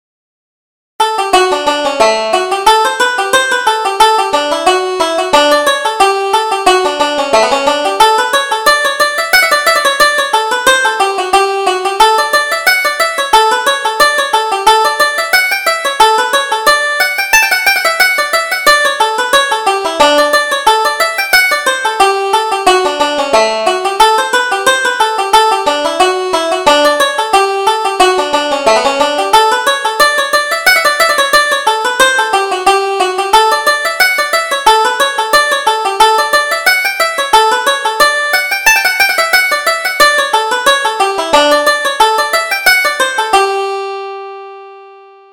Reel: Miss Fahey's Fancy